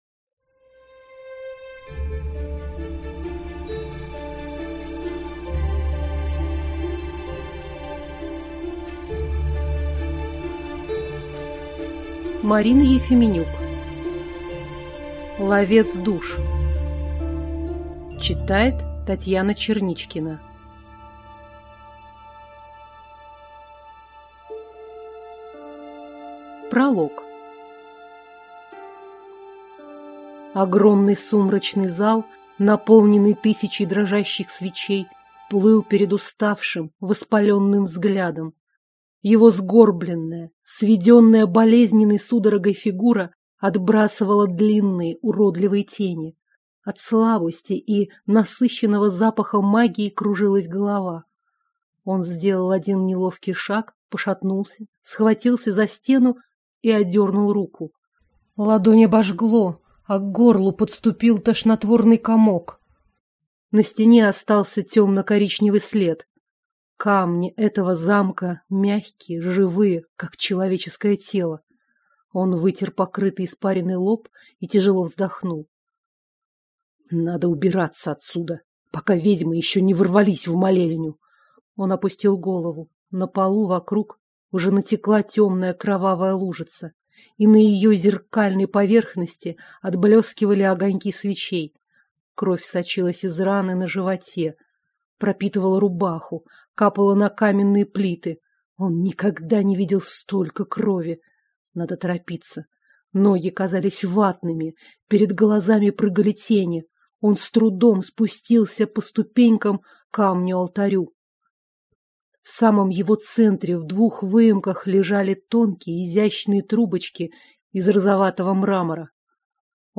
Аудиокнига Ловец Душ | Библиотека аудиокниг